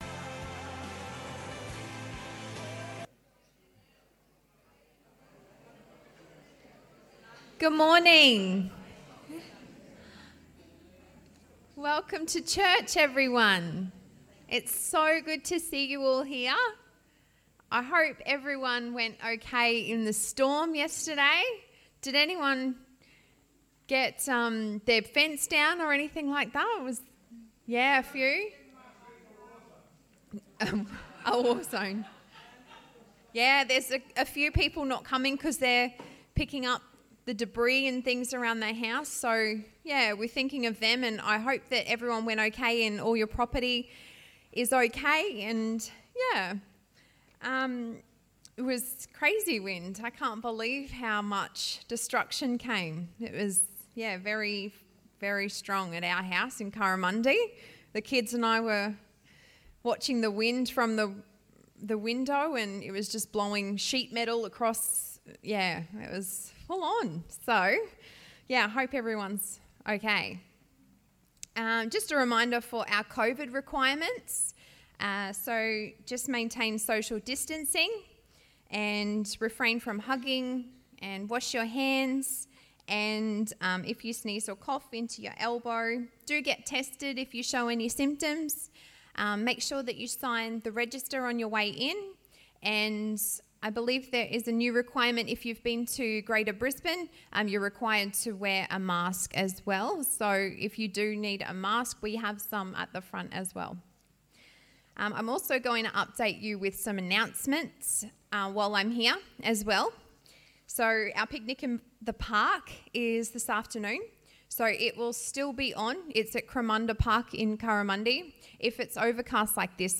Readings from Jeremiah 31:7-14 and 1 Peter 1:3-13 The sermon is in two parts